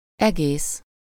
Ääntäminen
IPA: /ˈɛɡeːs/